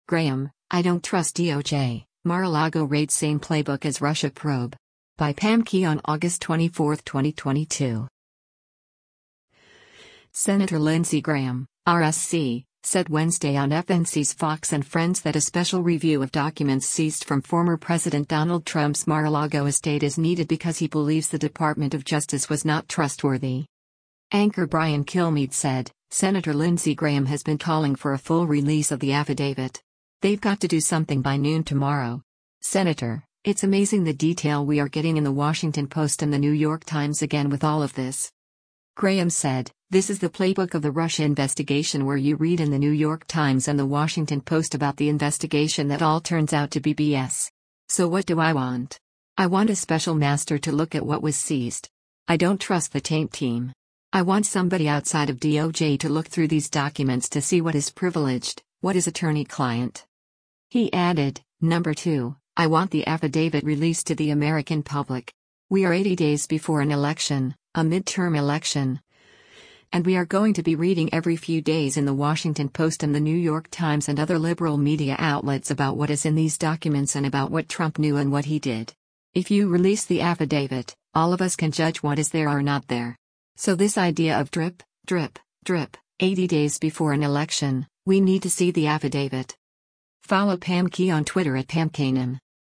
Senator Lindsey Graham (R-SC) said Wednesday on FNC’s “FOX & Friends” that a special review of documents seized from former President Donald Trump’s Mar-a-Lago estate is needed because he believes the Department of Justice was not trustworthy.